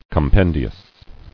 [com·pen·di·ous]